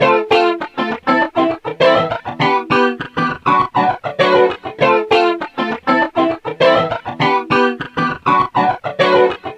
Sons et loops gratuits de guitares rythmiques 100bpm
Guitare rythmique 22